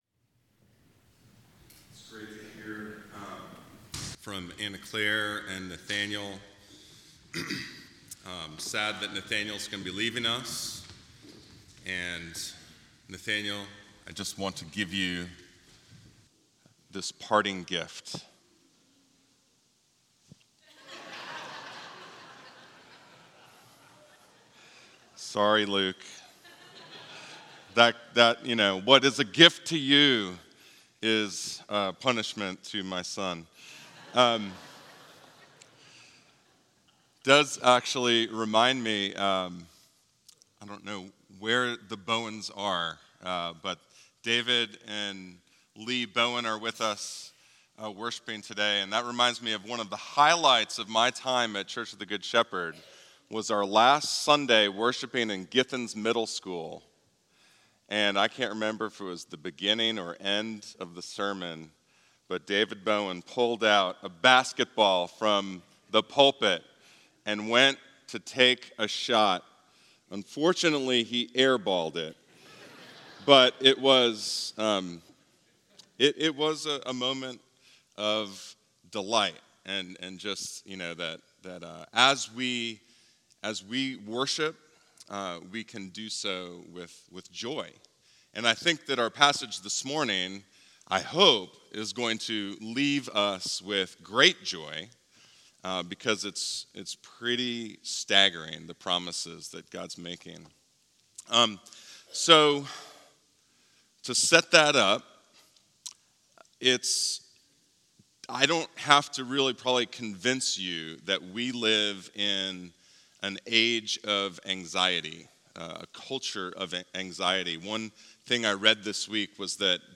CGS-Service-4-21-24.mp3